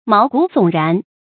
注音：ㄇㄠˊ ㄍㄨˇ ㄙㄨㄙˇ ㄖㄢˊ
毛骨悚然的讀法